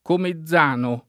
[ kome zz# no ]